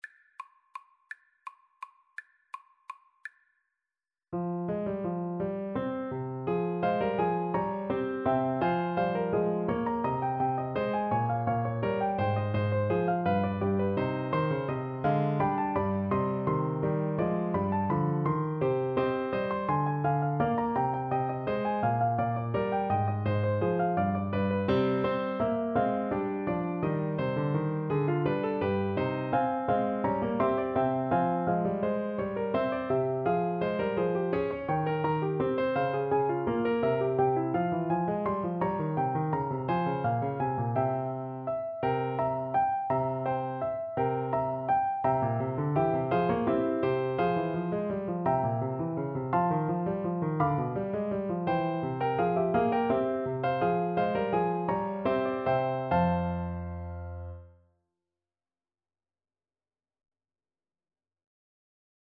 Play (or use space bar on your keyboard) Pause Music Playalong - Piano Accompaniment Playalong Band Accompaniment not yet available reset tempo print settings full screen
3/8 (View more 3/8 Music)
F minor (Sounding Pitch) (View more F minor Music for Flute )